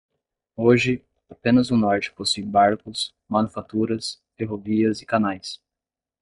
Manlik